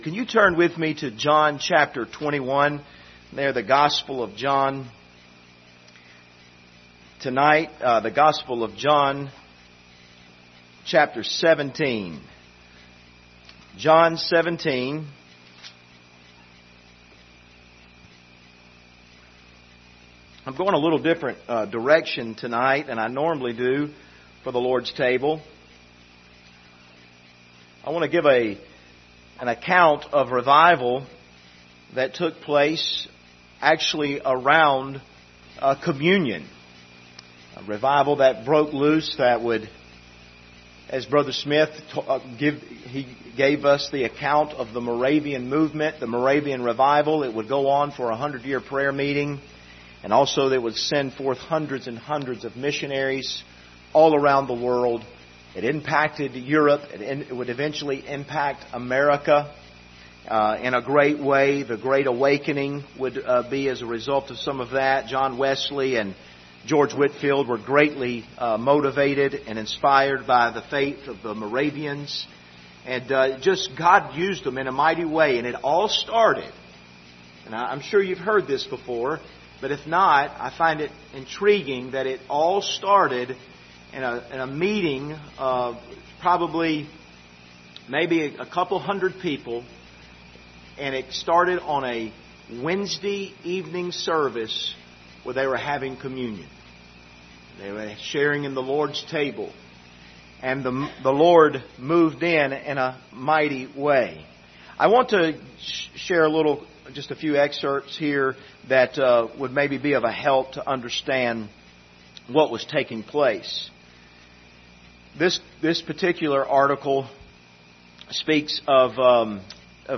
John 17:21 Service Type: Sunday Evening Topics: history , Lord's Supper , revival « Are You Living With Eternity In View?